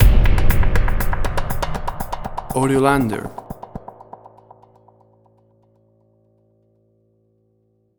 Suspense, Drama, Quirky, Emotional.
WAV Sample Rate: 16-Bit stereo, 44.1 kHz